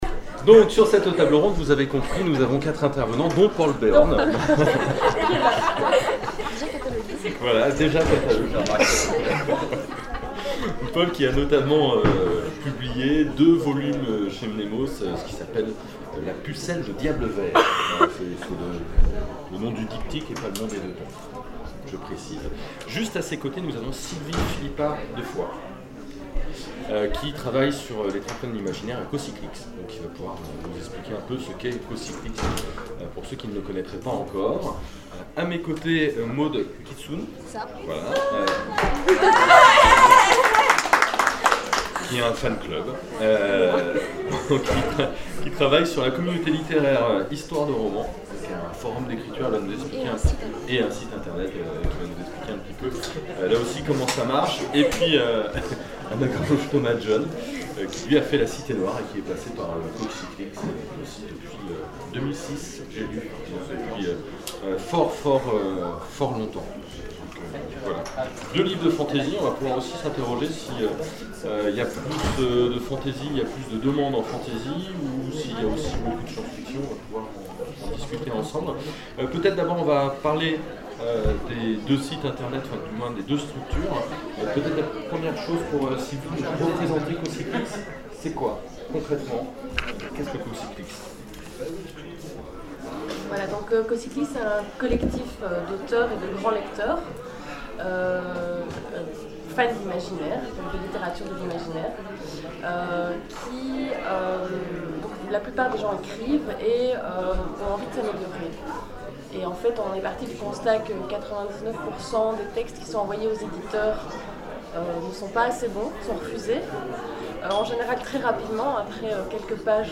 Zone Franche 2012 : Conférence Auteurs, faites-vous relire ! La bêta-lecture - ActuSF - Site sur l'actualité de l'imaginaire